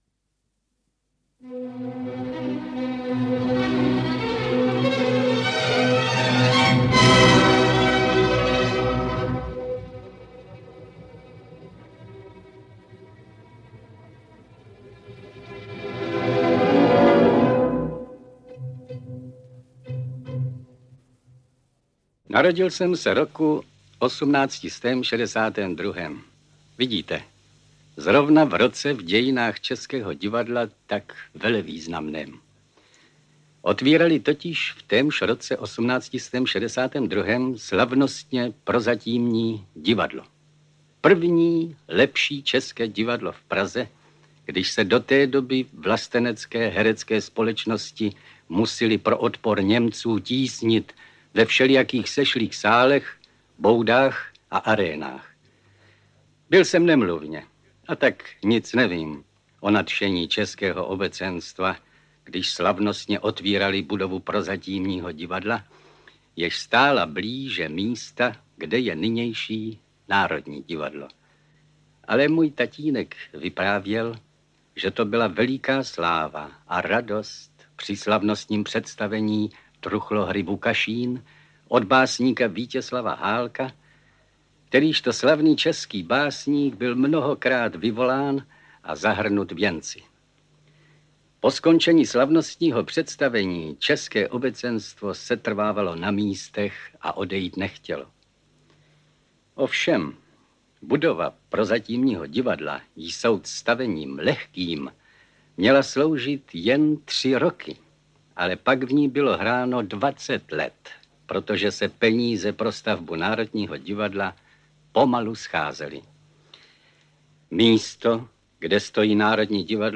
Rajský ostrov. Vyprávění o požáru a stavbě Národního divadla - vybrané kapitoly - Jaromír John - Audiokniha
• Čte: František Smolík